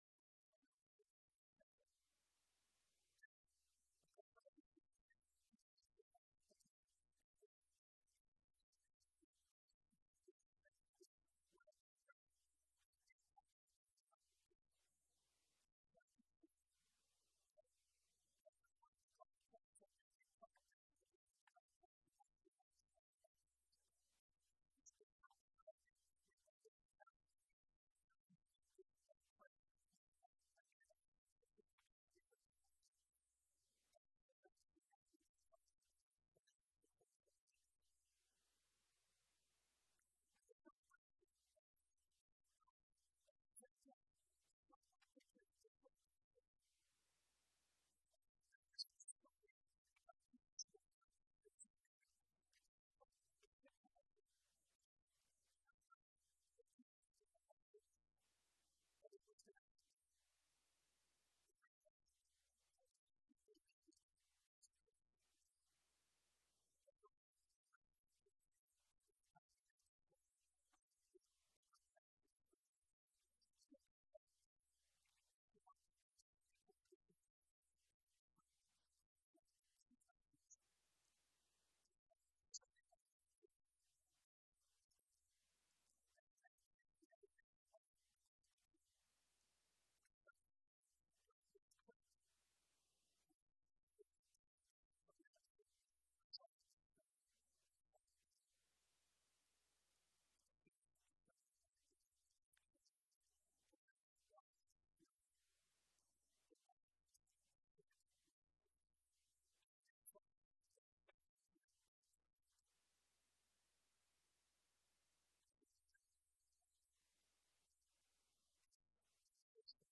Sermons | Georgetown Christian Fellowship
The message from our Easter Sunday service.